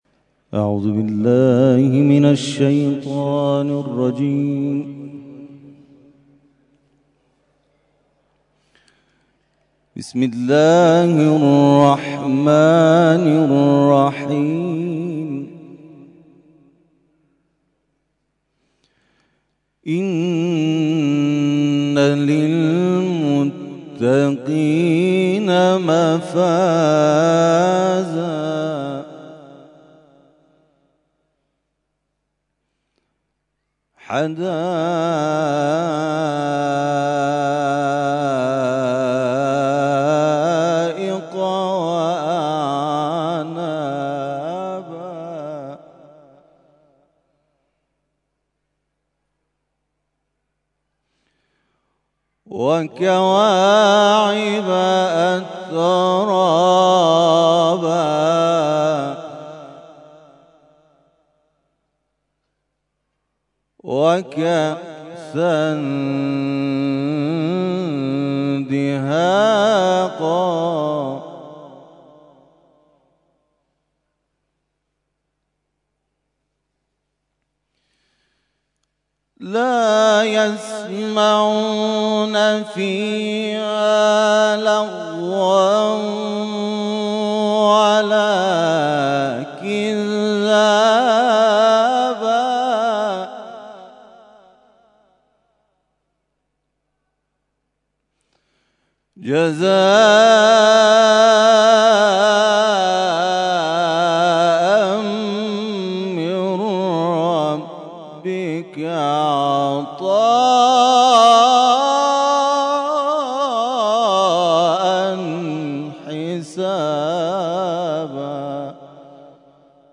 تلاوت ظهر